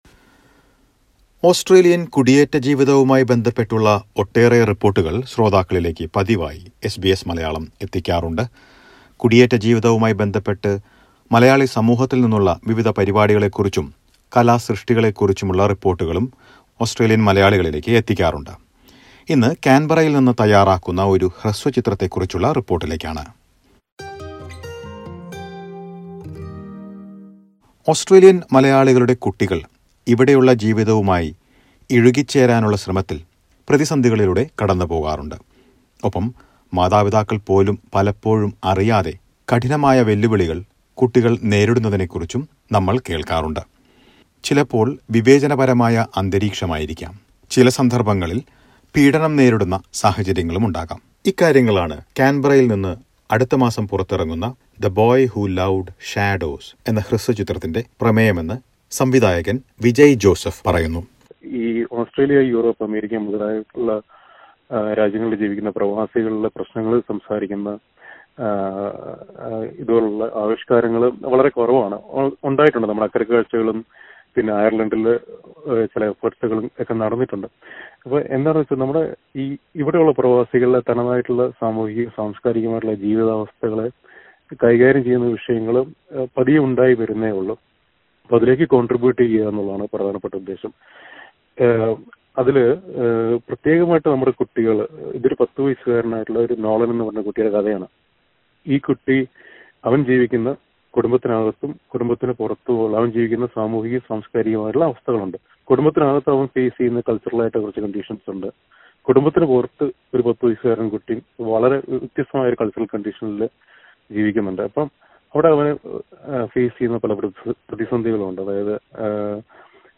Several reports suggest that migrant children go through different types of problems including discrimination and abuse. Listen to a report about a Malayalam short film from Canberra based on this topic.